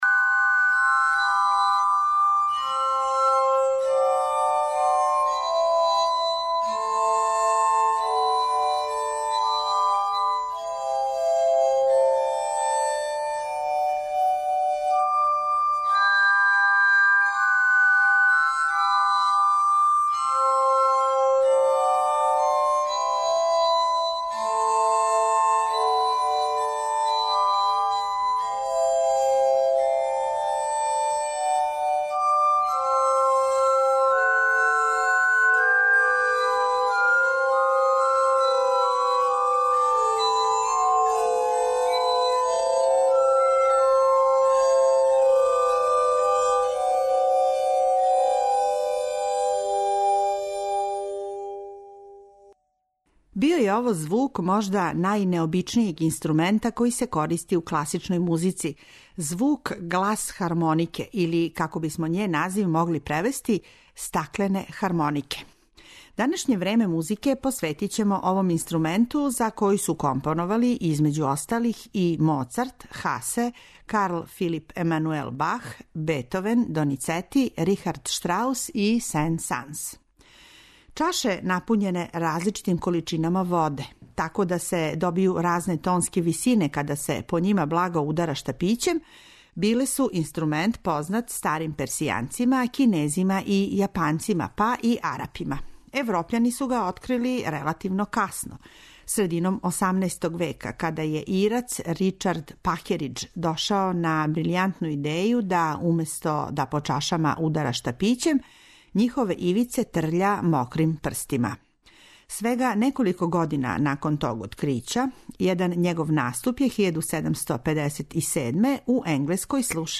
За гласхармонику су компоновали, између осталих, и Моцарт, Карл Филип Емануел Бах, Бетовен, Доницети, Рихард Штраус и Сен-Санс, а неке од тих композиција моћи ћете да чујете и у данашњој емисији.